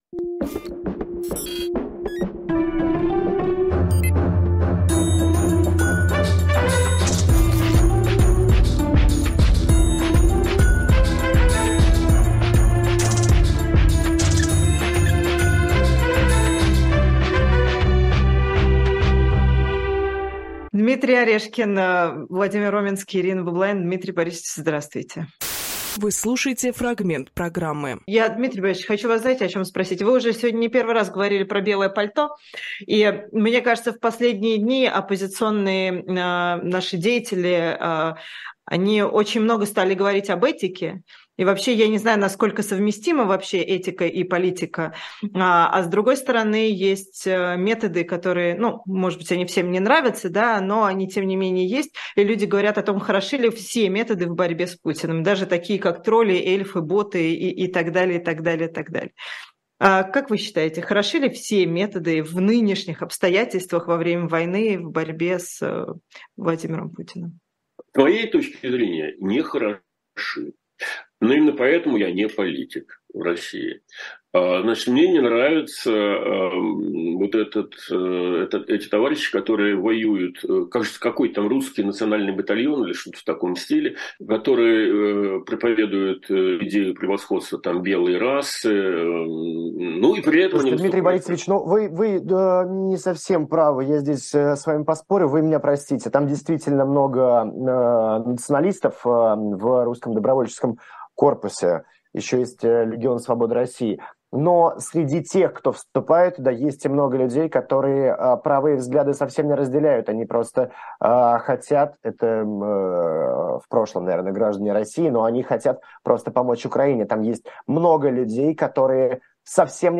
Дмитрий Орешкинполитолог
Фрагмент эфира от 20.11.2023